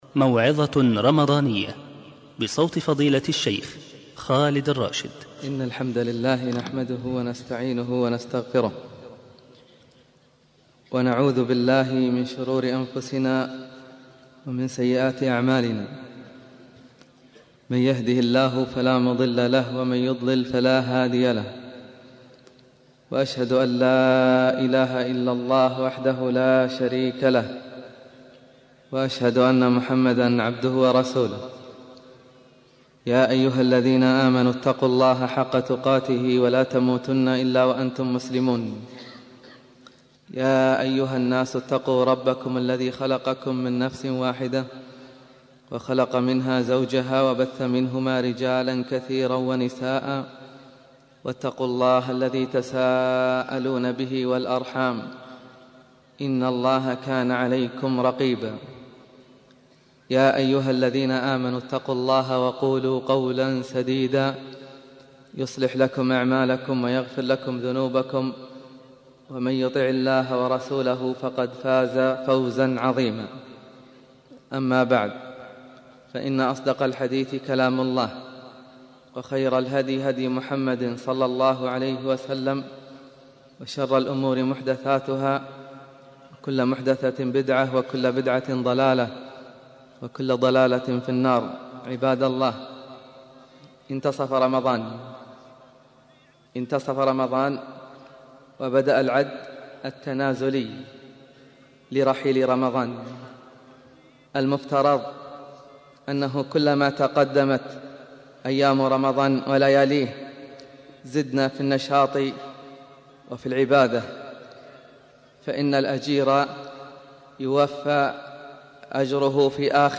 موعظة رمضانية
موعظة رمضانية.mp3